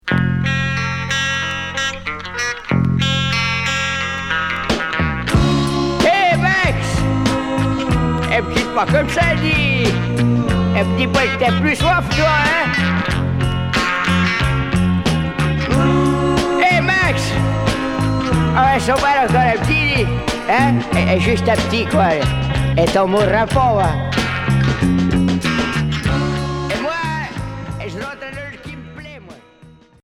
Beat